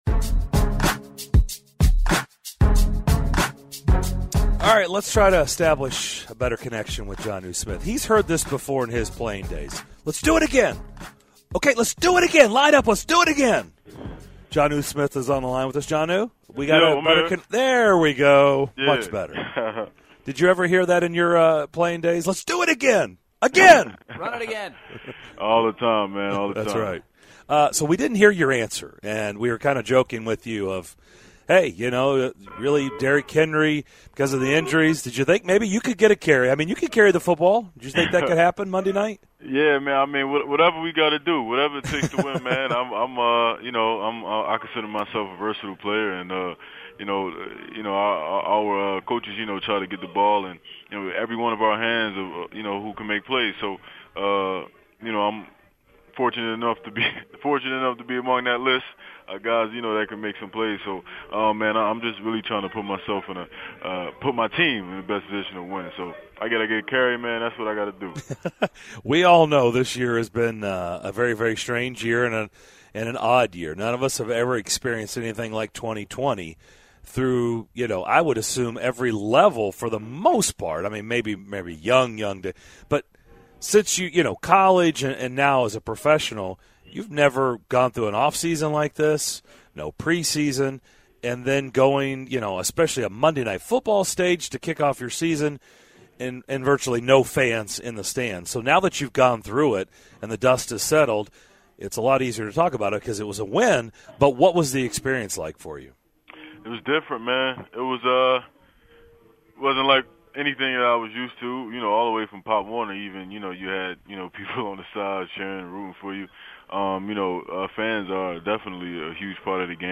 Tennessee Titans tight end Jonnu Smith joined DDC to discuss his team's 16-14 win over the Broncos, preparing for the Jaguars and more!